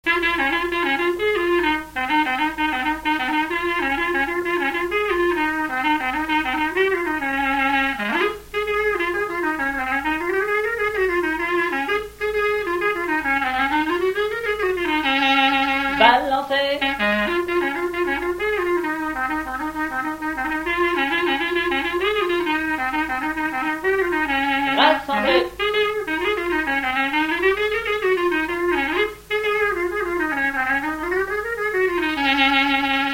Avant-deux
Résumé instrumental
danse : branle : avant-deux
Pièce musicale inédite